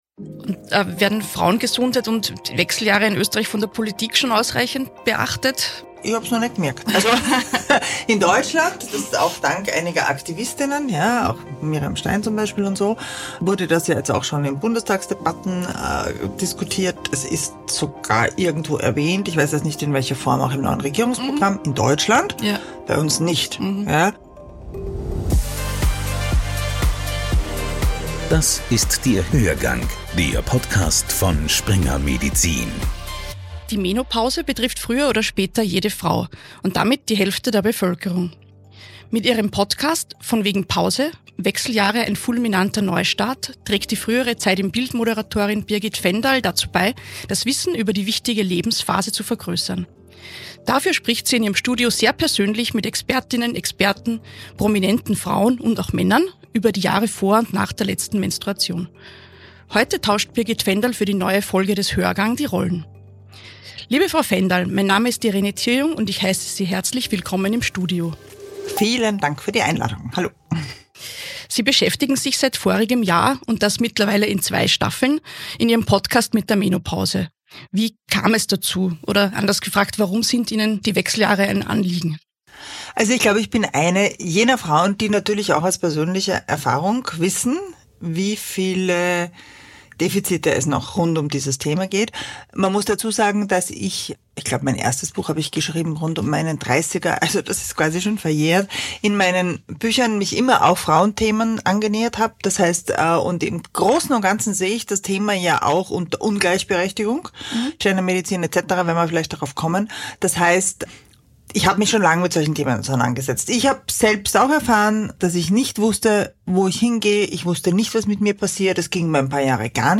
Ein Gespräch über eine Lebensphase, die kein Randthema ist – sondern die Hälfte der Gesellschaft betrifft.